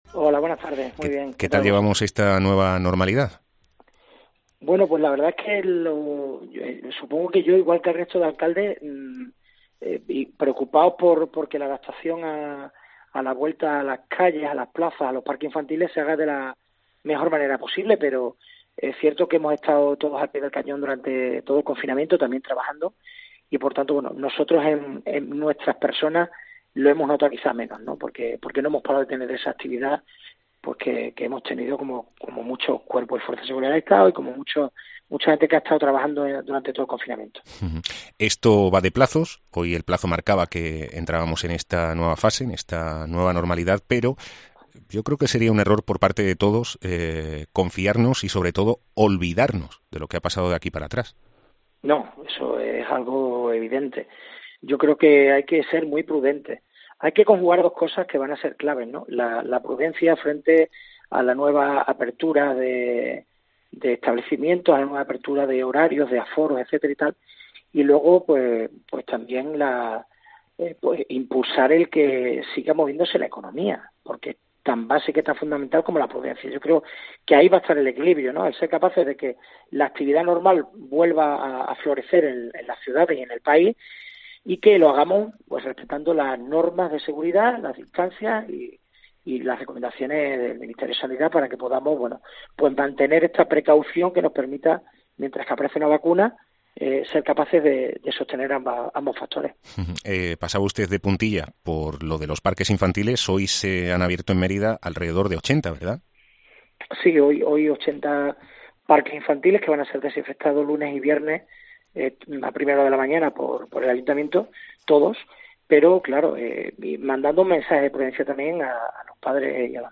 Entrevista a Antonio Rodrigez Osuna, Alcalde de Mérida